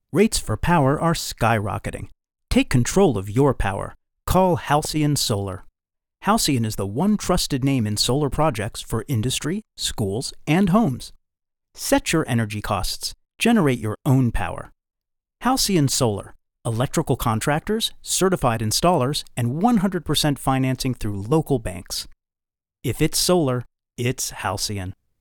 Engaging, Authentic, Articulate Storyteller
Halcyon Solar (radio spot)
General (Standard) American, Brooklyn
Middle Aged